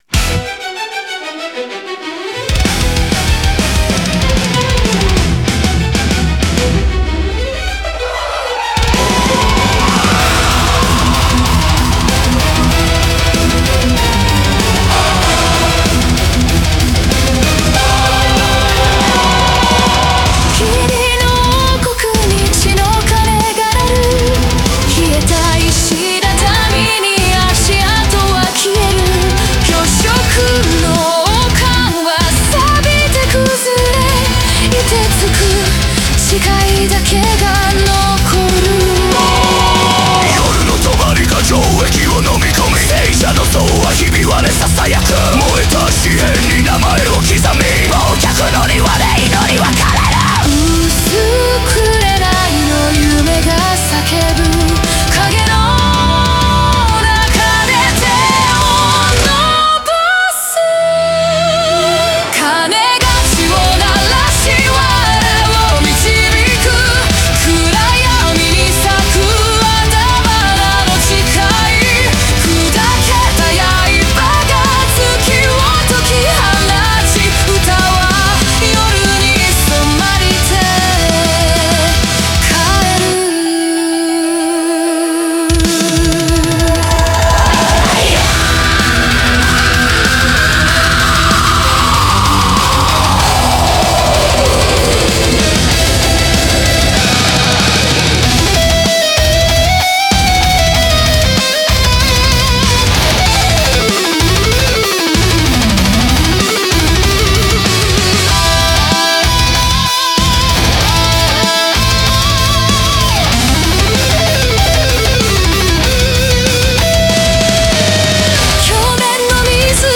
Symphonic Death Metal